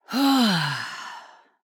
音效